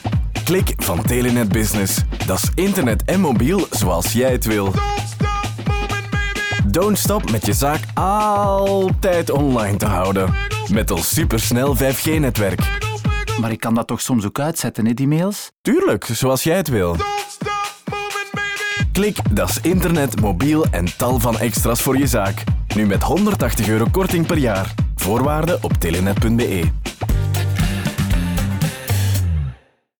La campagne, exclusivement en néerlandais, comprend également des spots radio, de l'OOH et un volet numérique.